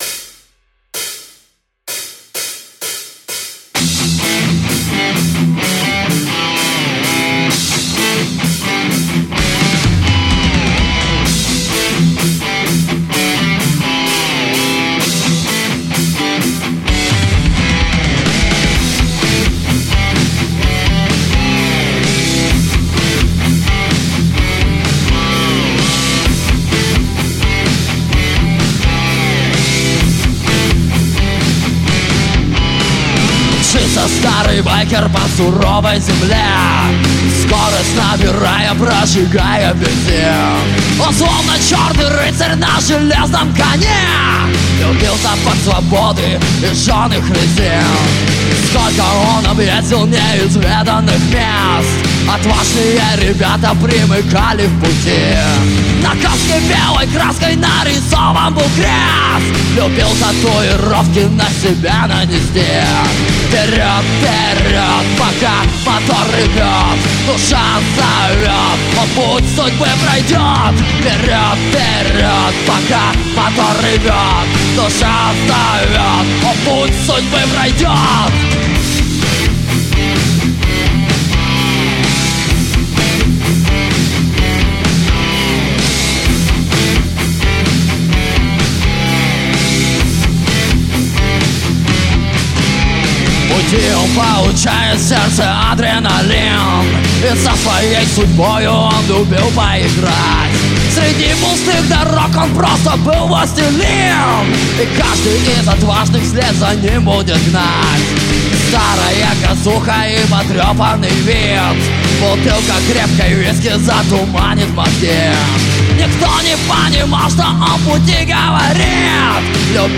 новодвинская power-metall группа.